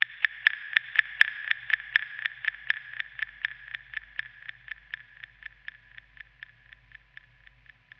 Tag: 120 bpm Weird Loops Fx Loops 1.35 MB wav Key : Unknown